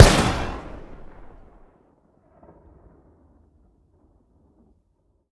weap_mike203_fire_plr_atmos_04.ogg